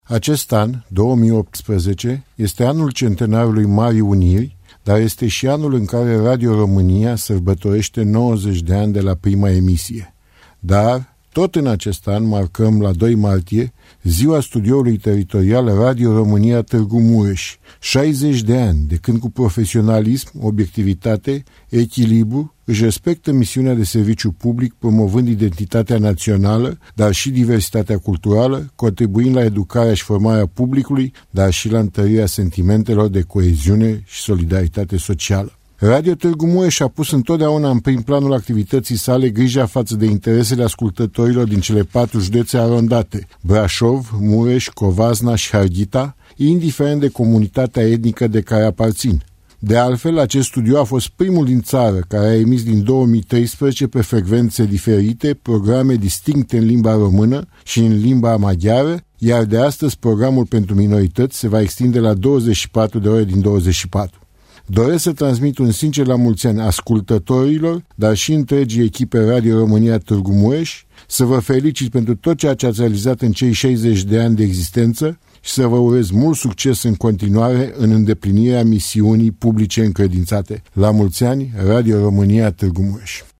Președintele director general al Societății Române de Radiodifuziune, Georgică Severin, a transmis un mesaj cu ocazia împlinirii celor 60 de ani a Radio Tîrgu-Mureș: